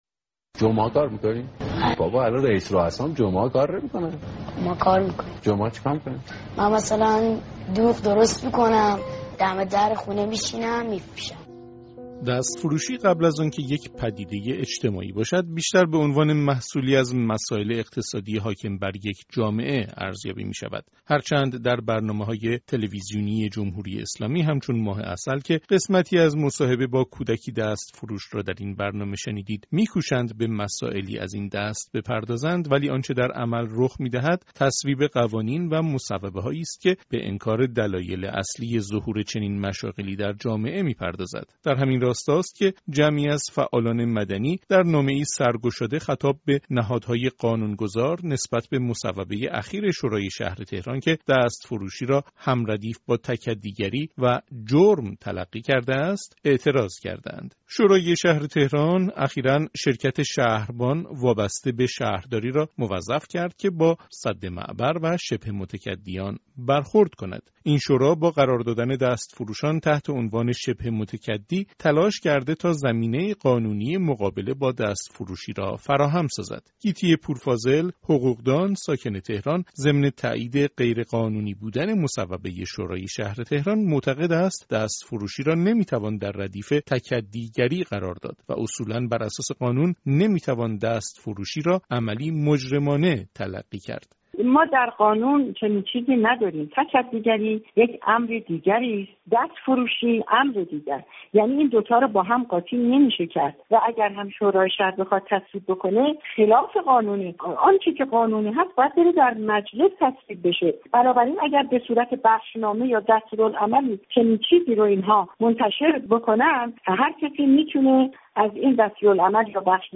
گزارش رادیویی